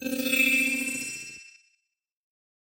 На этой странице собраны звуки голограмм — загадочные и футуристические аудиоэффекты, напоминающие технологии из научной фантастики.
Звук голограммы, возникшей извне